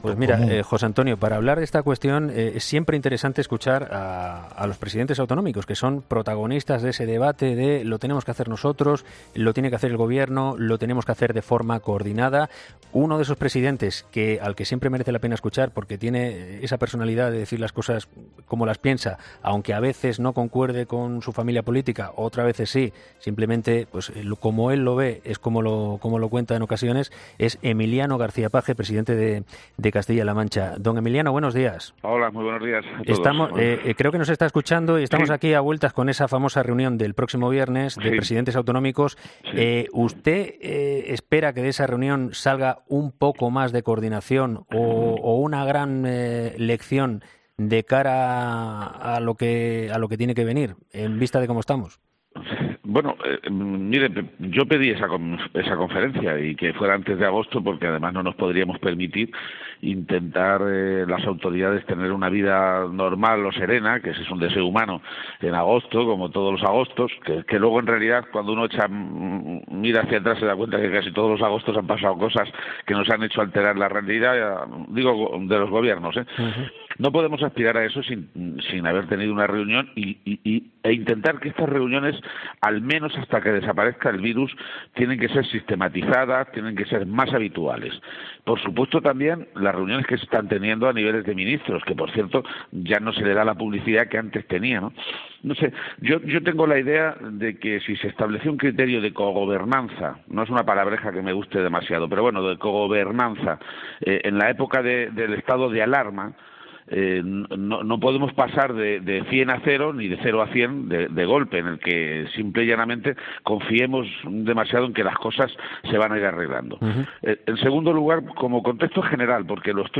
Emiliano García-Page, presidente de Castilla-La Mancha, ha sido entrevistado este lunes en 'Herrera en COPE', donde se ha pronunciado sobre la conferencia de presidentes que Pedro Sánchez ha convocado este viernes para abordar los rebrotes de la pandemia y acordar la participación de las comunidades en el fondo europeo de recuperación.